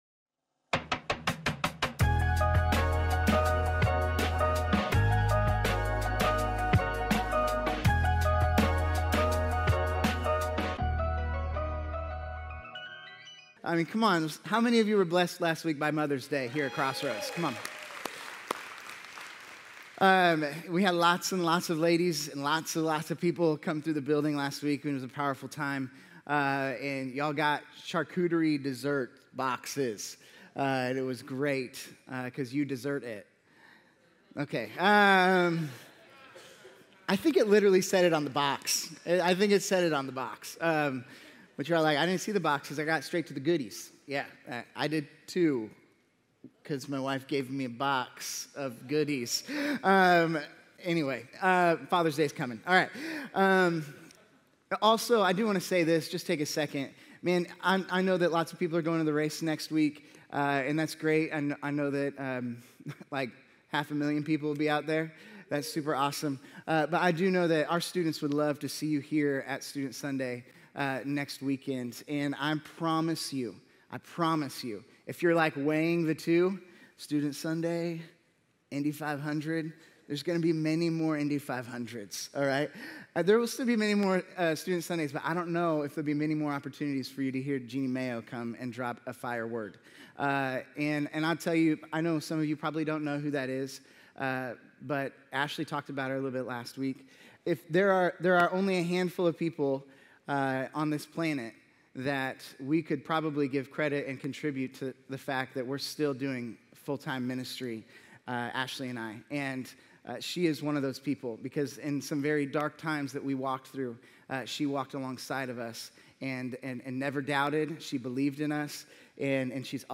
A message from the series "Guest Sessions."